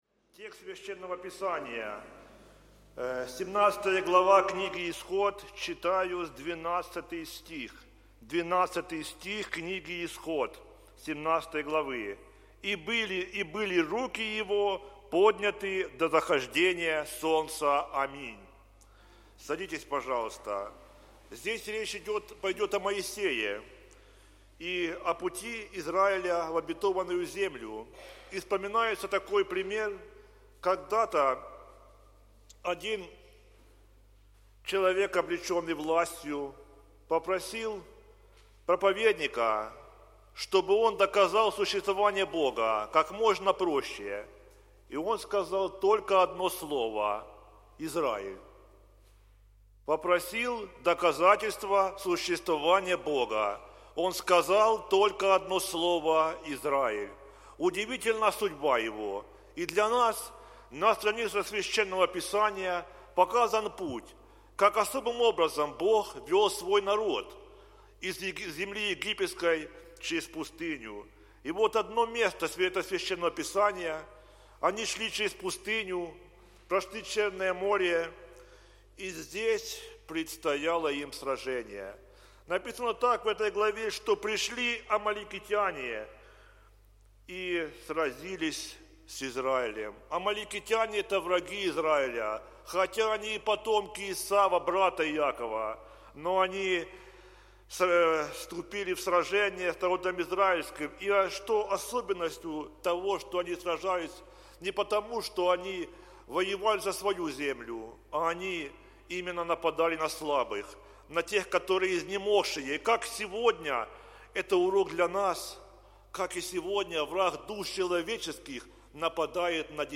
Проповеди
Сайт Московской Центральной церкви Евангельских христиан-баптистов.